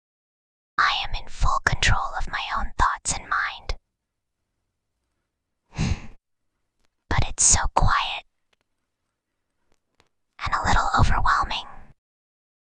Whispering_Girl_14.mp3